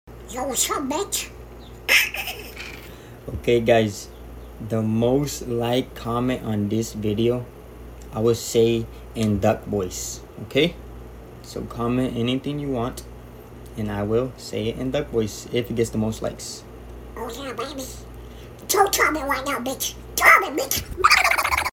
i’ll say whatever you want in the ducky voice